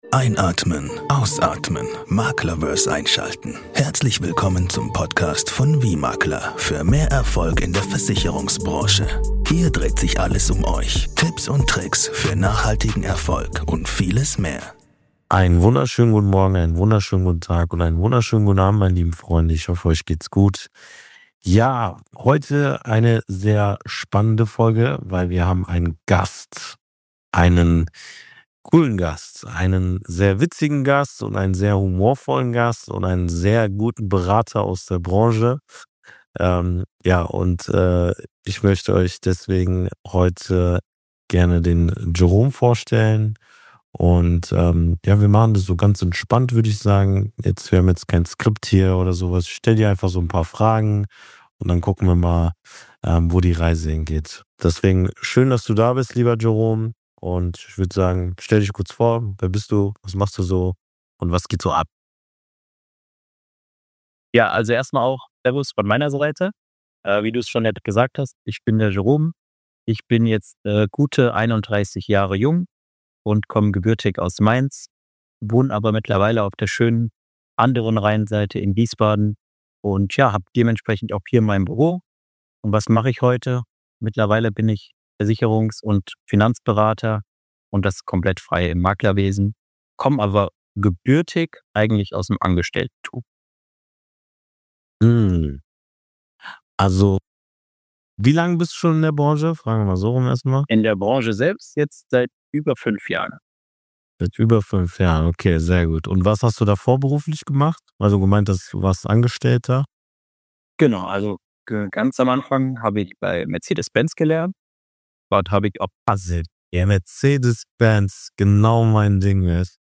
Ein ehrliches Gespräch über Mut, Veränderung und die Entscheidung, nicht im System zu verharren – sondern den eigenen Weg zu gehen.